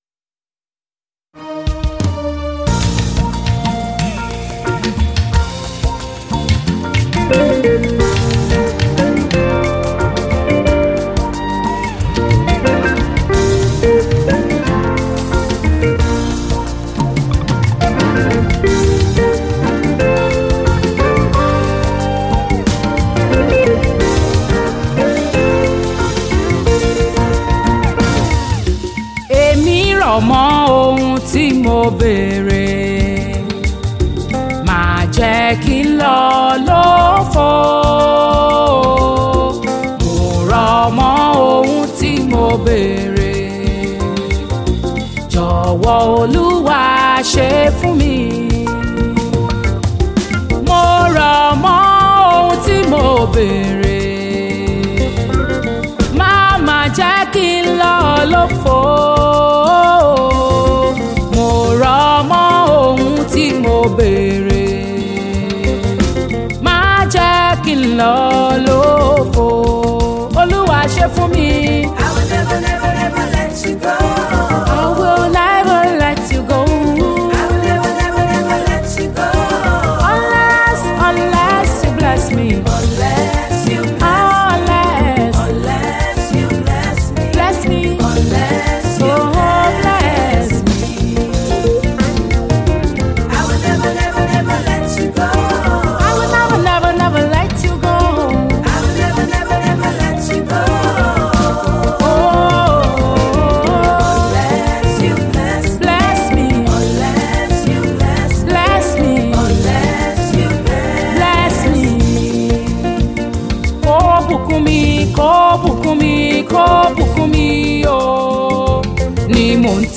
Sensational Nigerian gospel tune sensation